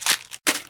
trashcan1.ogg